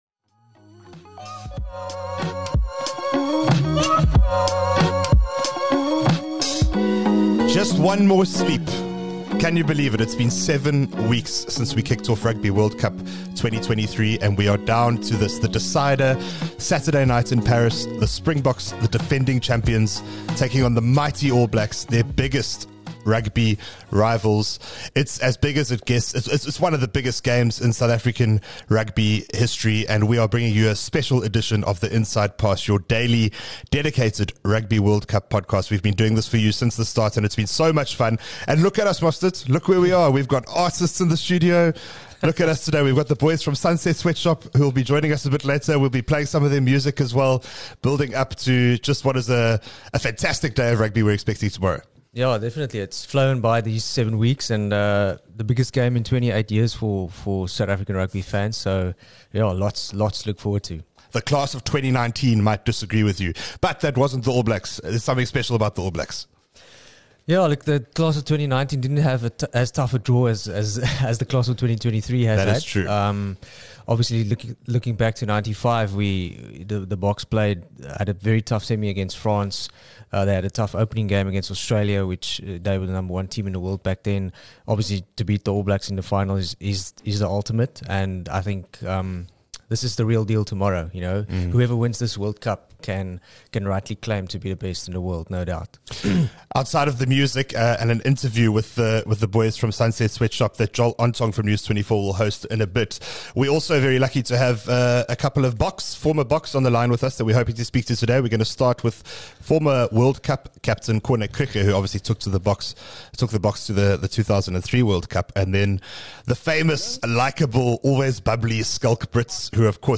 Former Springbok captain Corne Krige and the five-piece indie group Sunset Sweatshop are on the guest list.